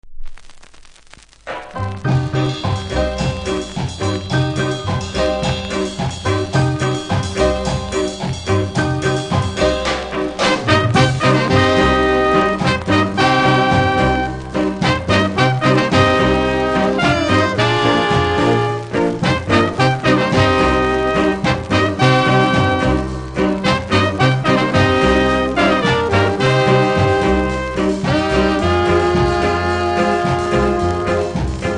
CONDITION：G ( 全体的にプレスノイズあり )
残念ながらコンディション良くありませんので試聴で確認下さい